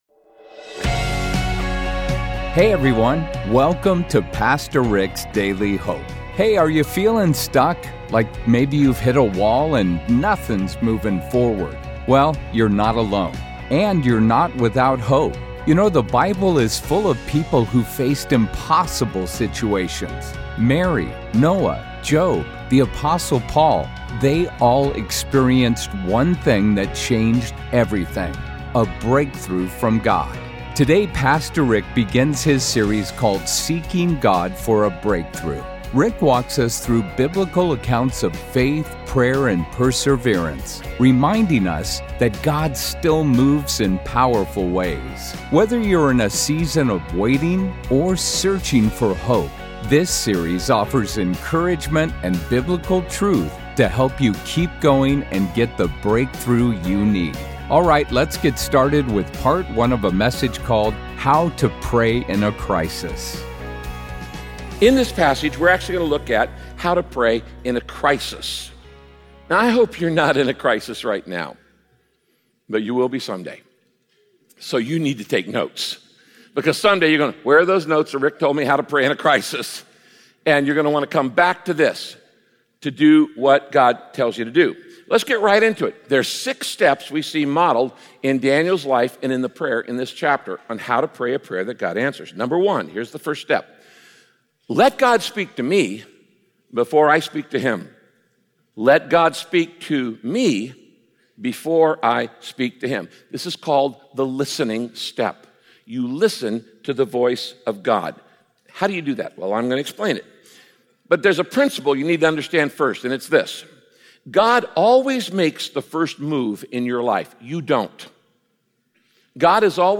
The best thing to do during times of crisis is turn your face toward God. In this message, Pastor Rick looks at the life of Daniel for insight into how to lead…